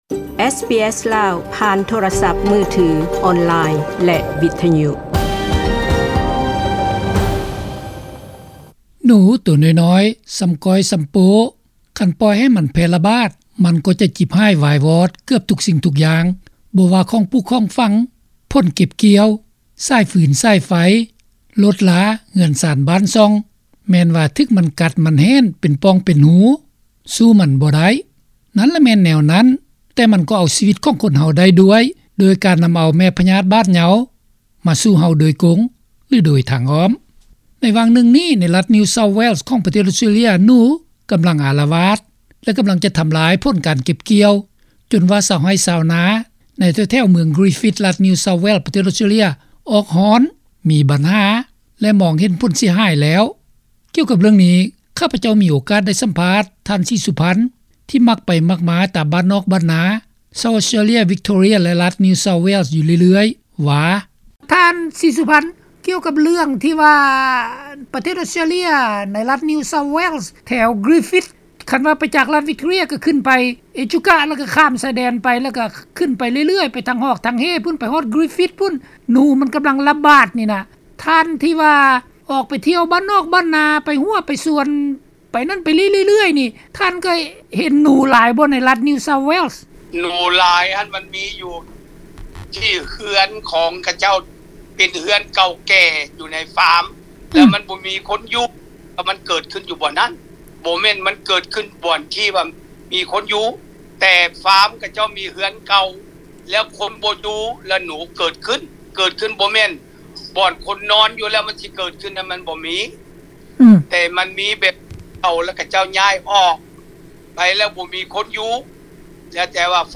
ສຳພາດທ່ານ